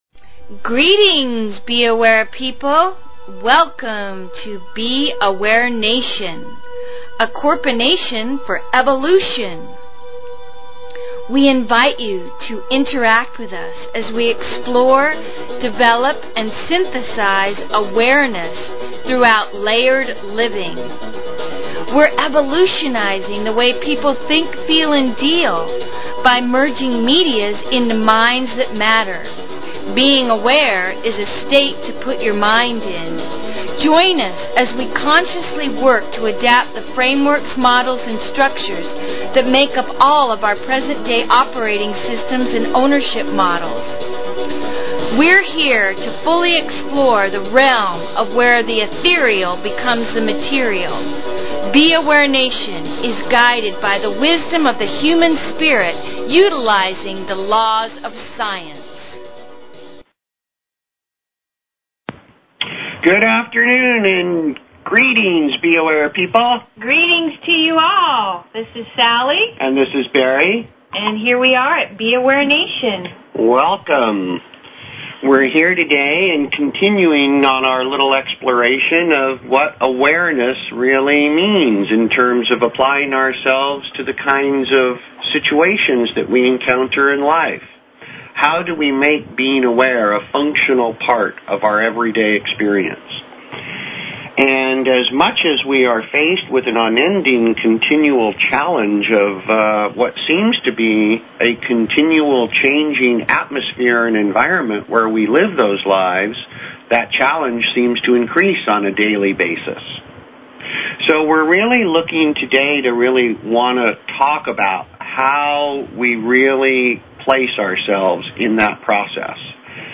Talk Show Episode, Audio Podcast, B_Aware_Nation and Courtesy of BBS Radio on , show guests , about , categorized as